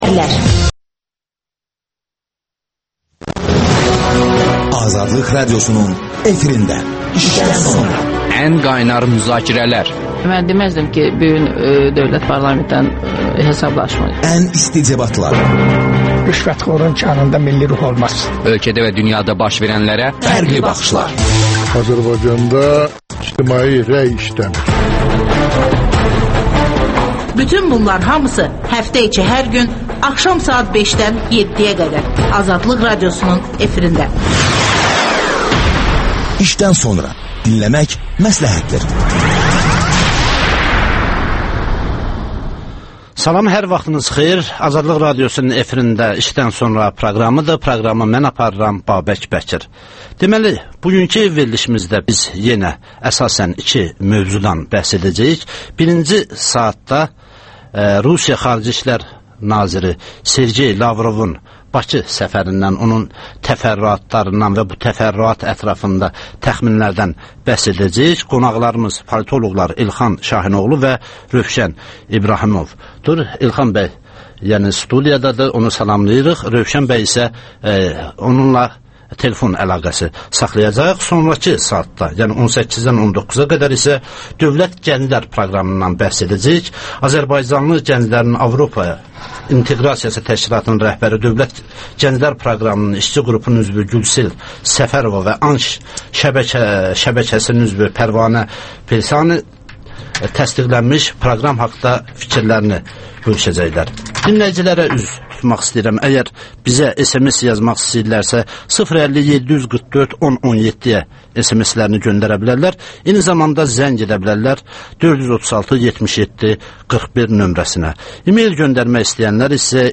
Politoloqlar